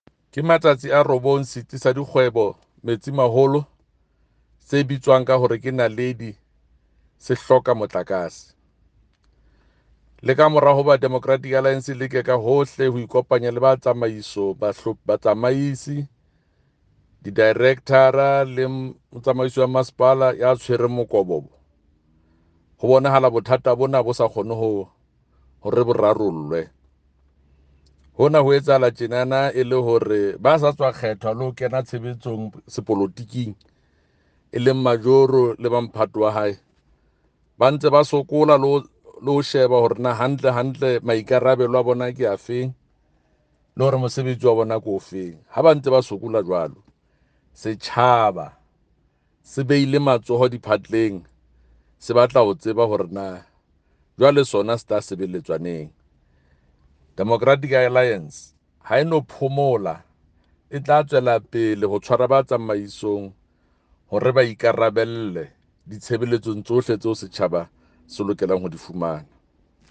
Sesotho by Jafta Mokoena MPL.